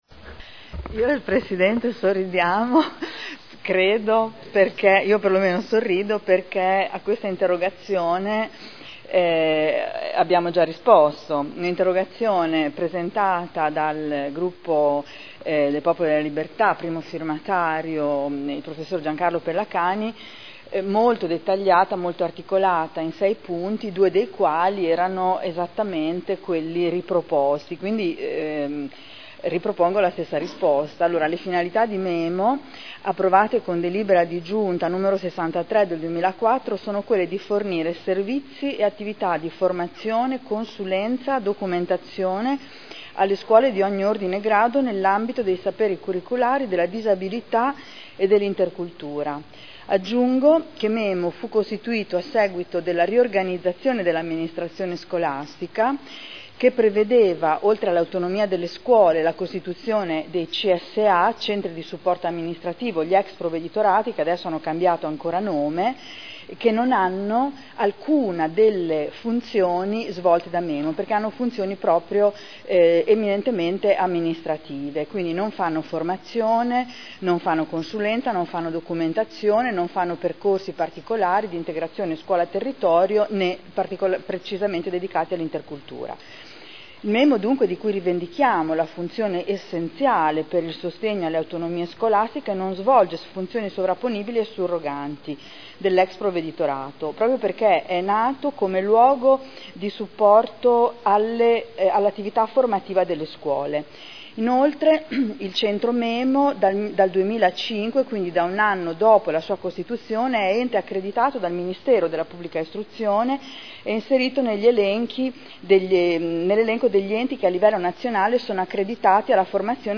Seduta del 22/12/2011. Risponde alle 2 Interrogazioni del consigliere Barberini (Lega Nord) avente per oggetto: “MEMO”